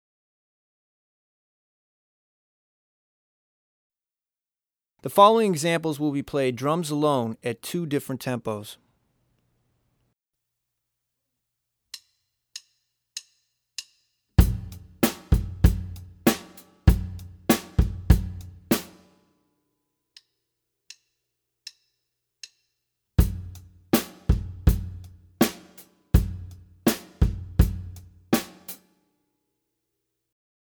Voicing: Drum Set